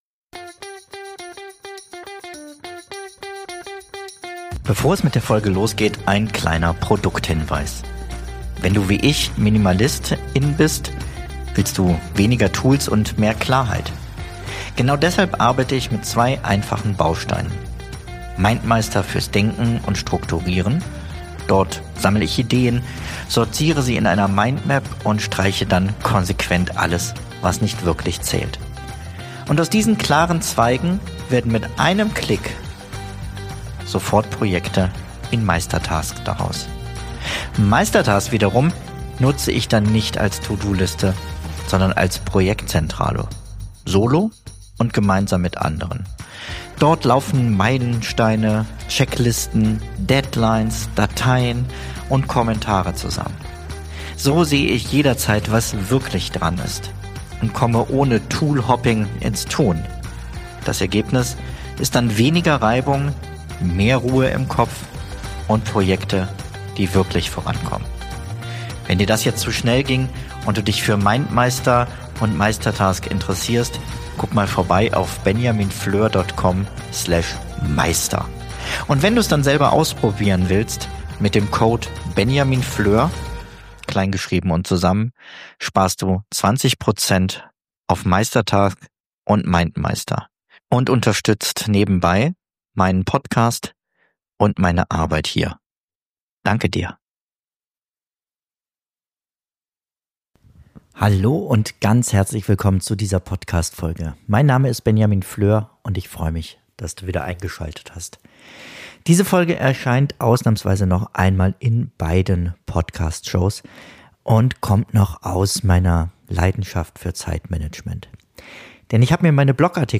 Gemeinsam mit meinen KI-Co-Hosts analysiere ich, welche Funktionen im Alltag wirklich zählen, von Zeiterfassung über Automatisierung bis hin zu Datenschutz und Mindmap-Integration. Ich teile meine persönlichen Erfahrungen und zeige auf, wo die Unterschiede liegen – und für wen sich welches System eignet.